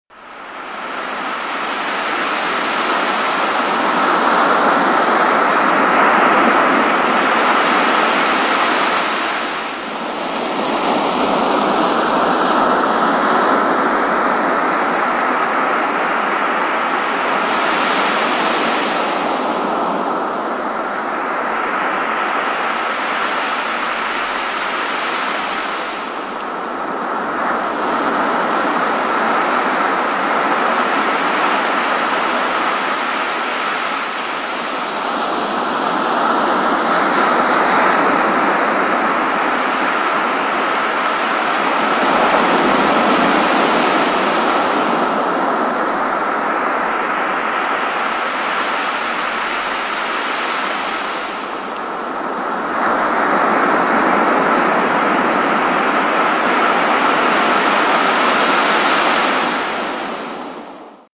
Relaxing Ocean Sounds 2
Category: Animals/Nature   Right: Personal